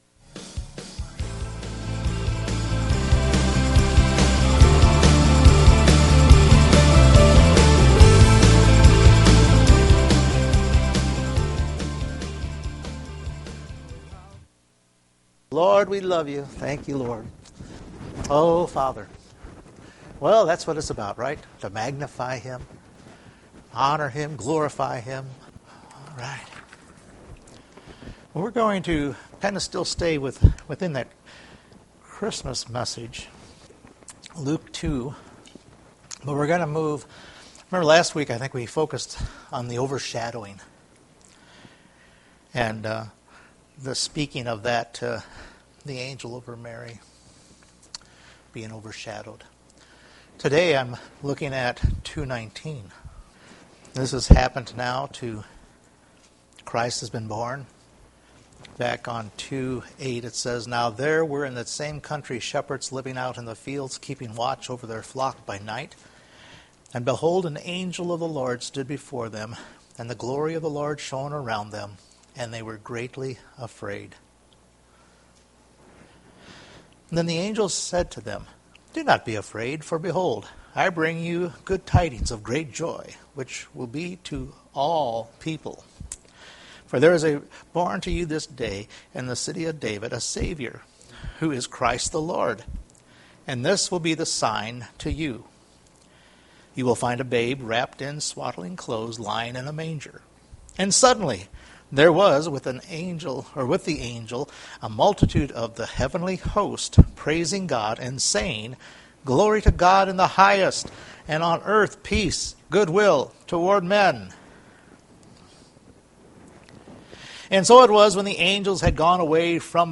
Luke 2:19 Service Type: Sunday Morning Mary pondered what the Shepheard’s shared in Luke 2:19.